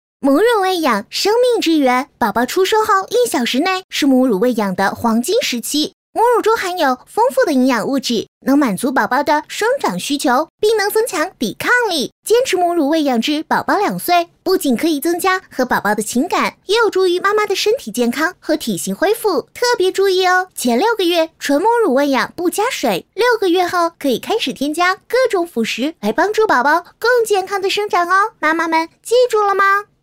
女国168_动画_童声_女童-稍长女童.mp3